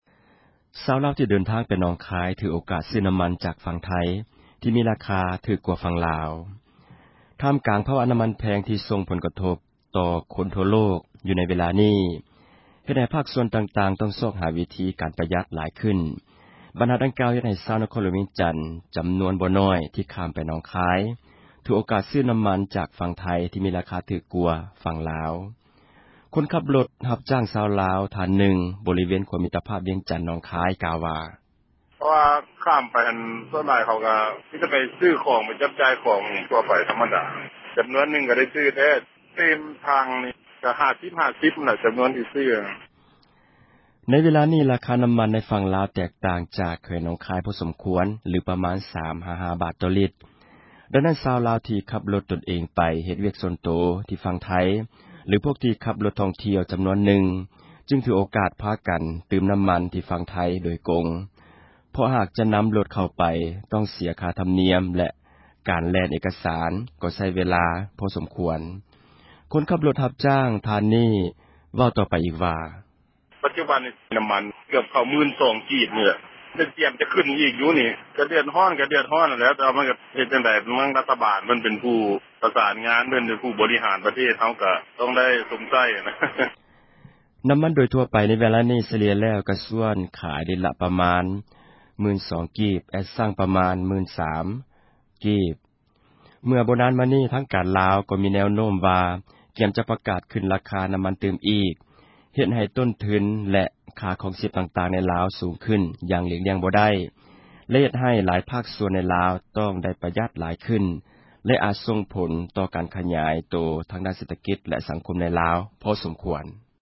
ຄົນ ຂັບຣົຖ ຮັບຈ້າງ ຊາວລາວ ທ່ານ ນຶ່ງ ບໍຣິເວນ ຂົວມິຕພາບ ວຽງຈັນ - ໜອງຄາຍ ກ່າວ ວ່າ: